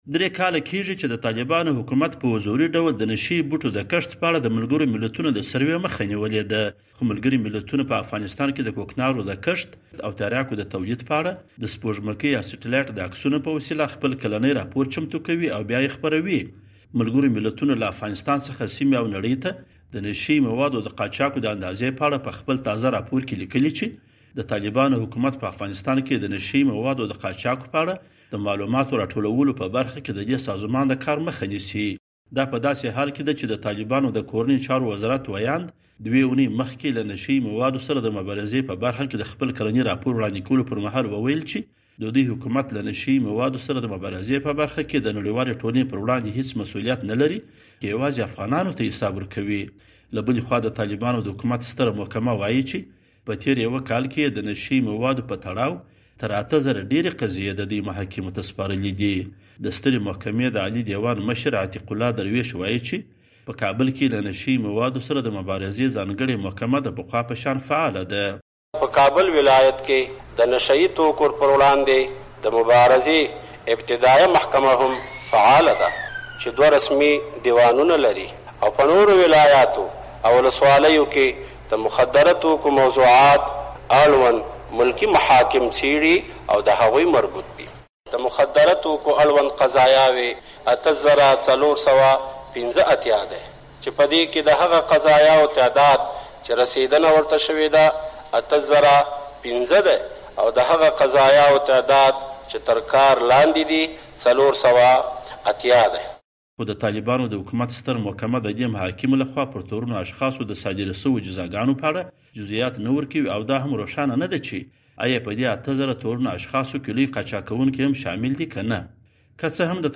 د ملګرو ملتونو راپور